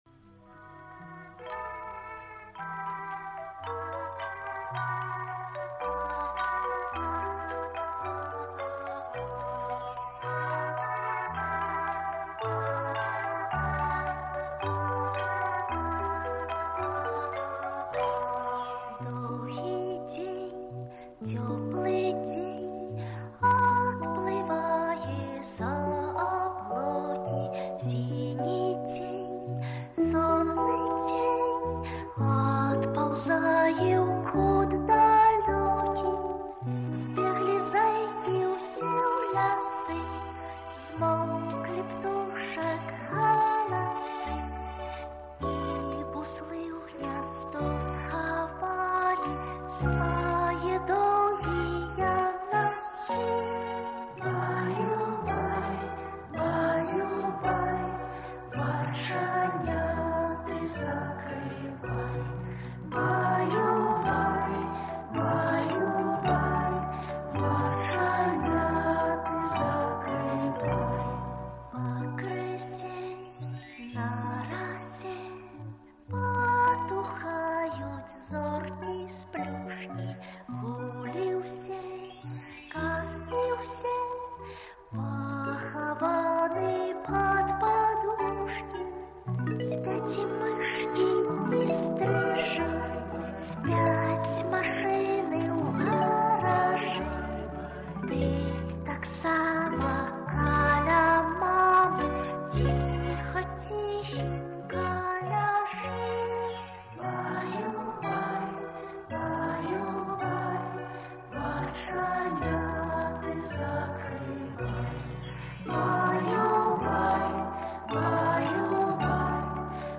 Колыбельная на белорусском языке 🇧🇾
Детская колыбельная - Калыханка (на белорусском)